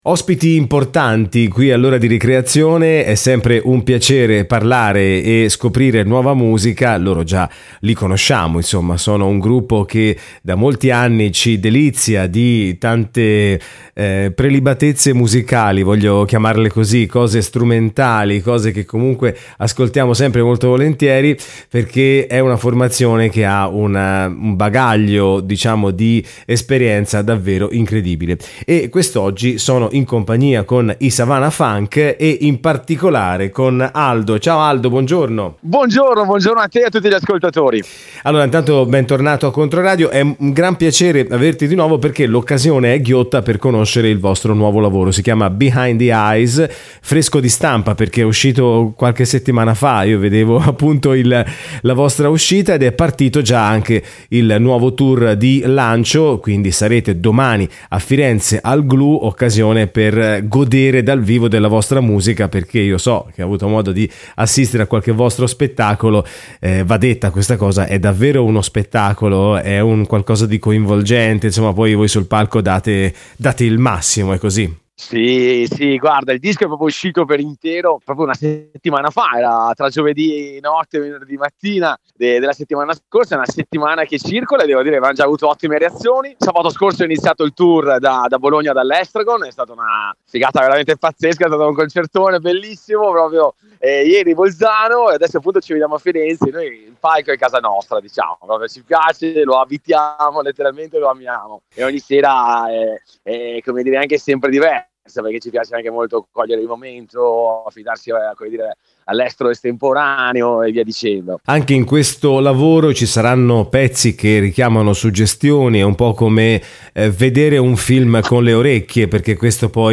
🎧 Savana Funk, il nuovo disco e il concerto a Firenze. Ascolta l'intervista.